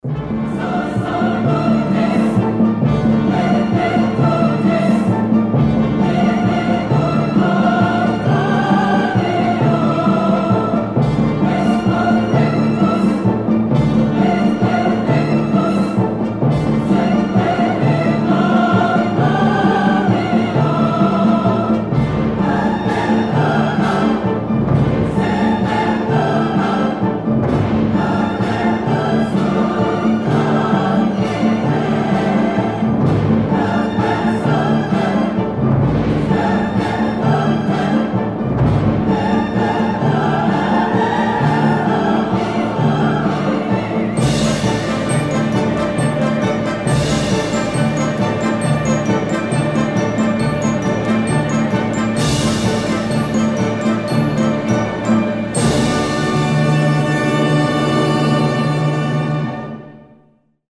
классические